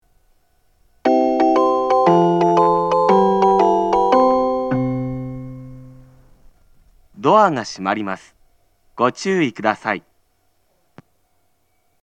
発車メロディー
スイッチを一度扱えばフルコーラス鳴ります。